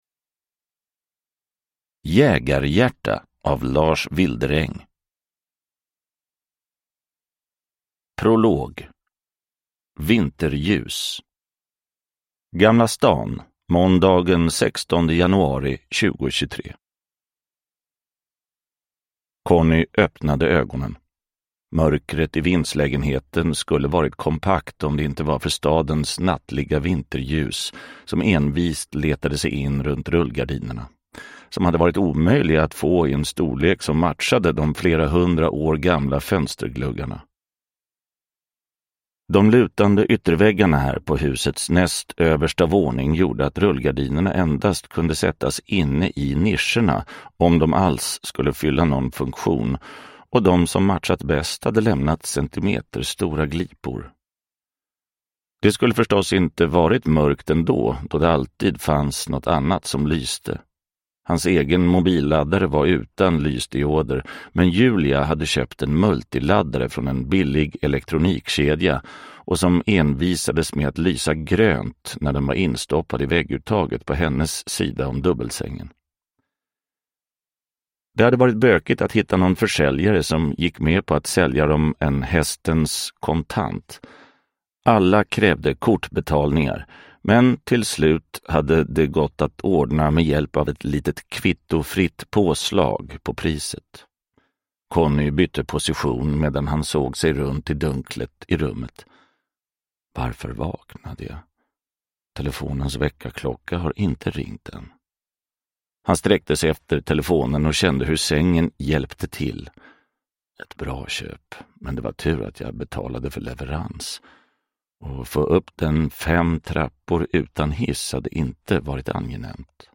Jägarhjärta – Ljudbok